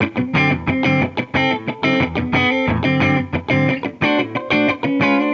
Index of /musicradar/sampled-funk-soul-samples/90bpm/Guitar
SSF_TeleGuitarProc1_90D.wav